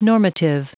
Prononciation du mot normative en anglais (fichier audio)
Prononciation du mot : normative